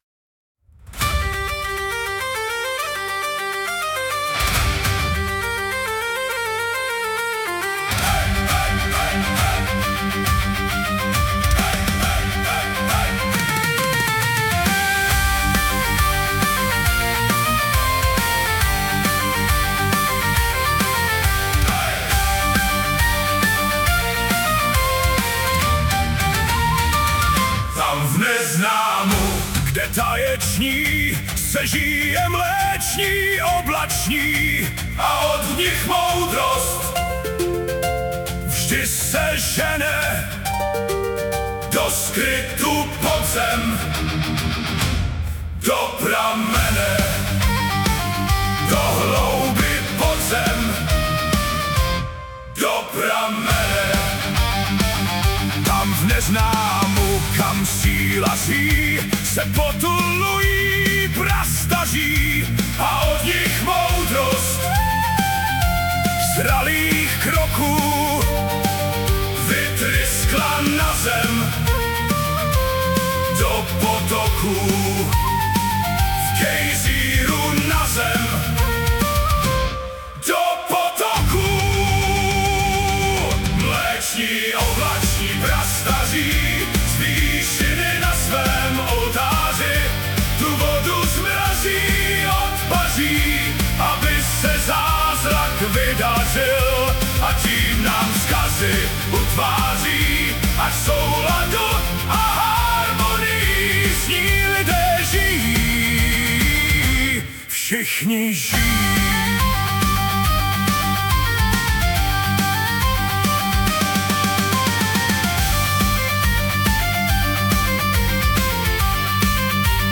Anotace: zhudebněná rozšířená verze